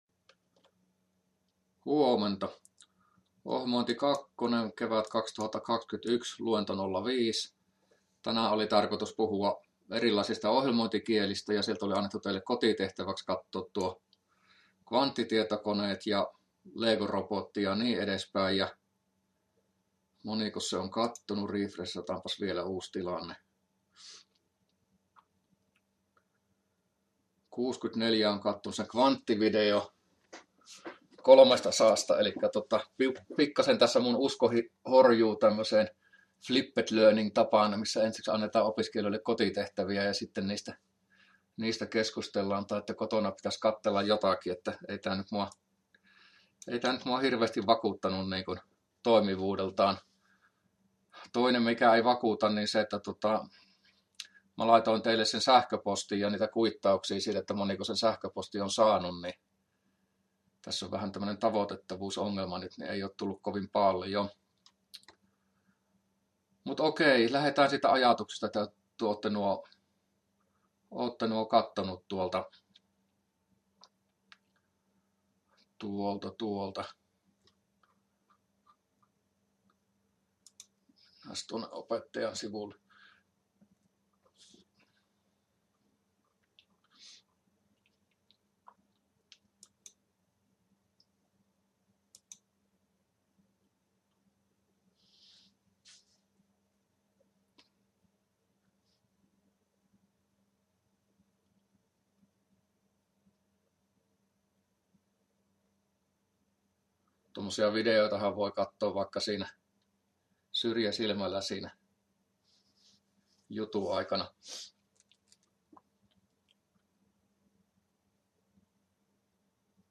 luento05a